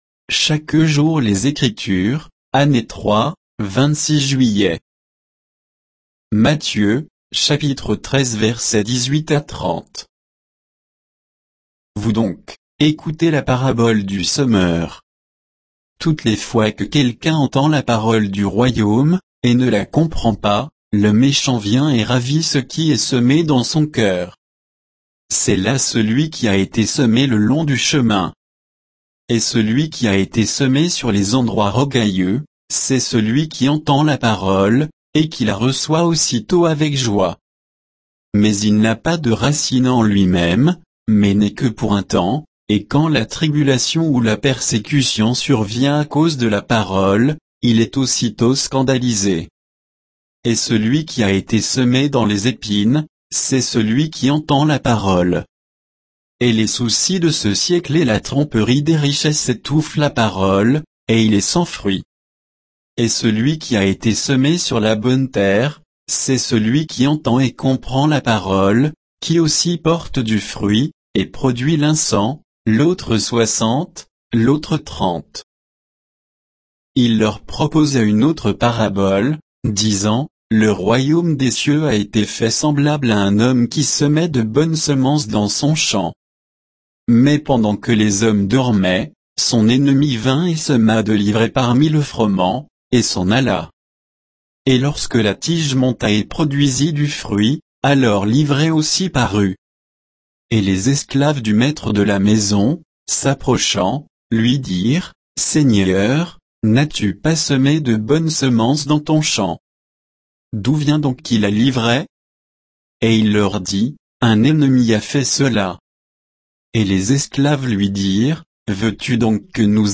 Méditation quoditienne de Chaque jour les Écritures sur Matthieu 13